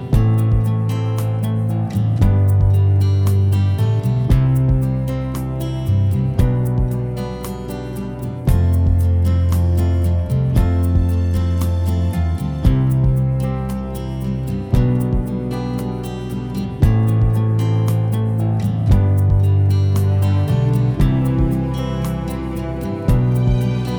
No Backing Vocals Soundtracks 4:36 Buy £1.50